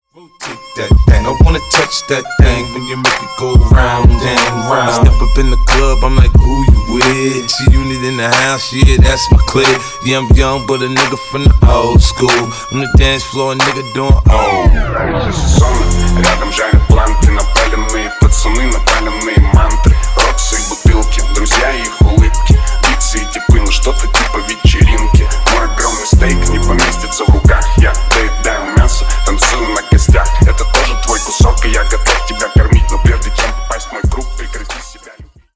Ремикс # Рэп и Хип Хоп